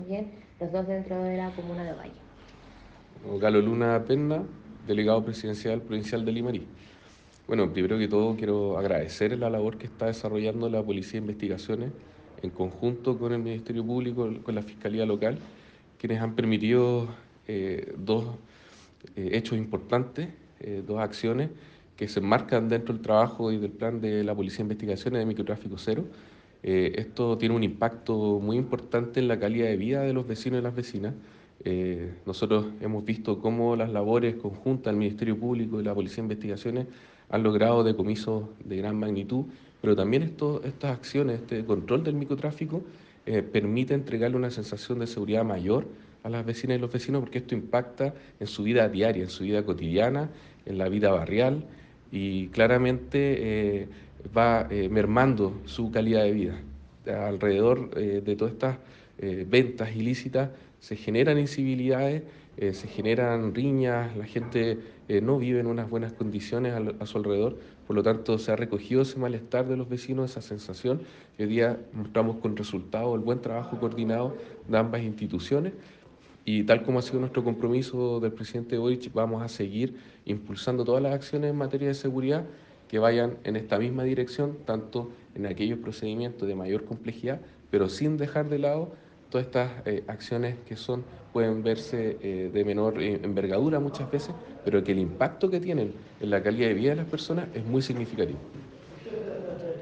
DELEGADO-PROV.mp3